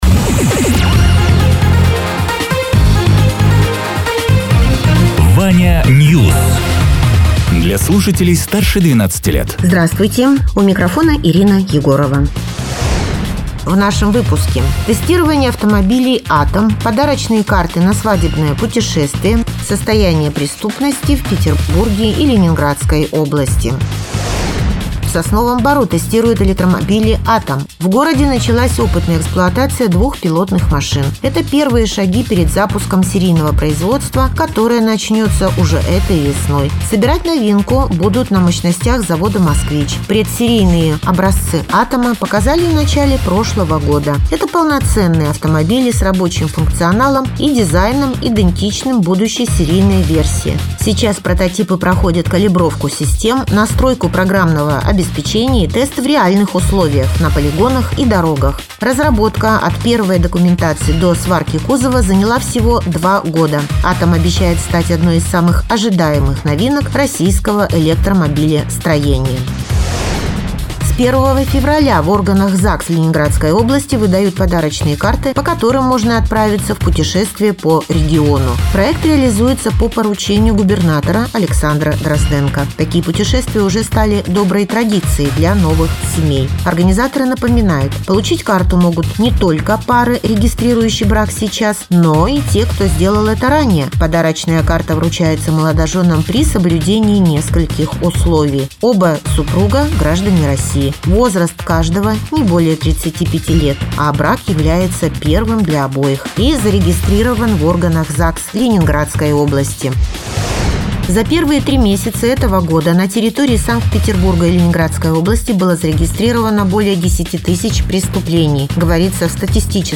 Радио ТЕРА 14.04.2026_10.00_Новости_Соснового_Бора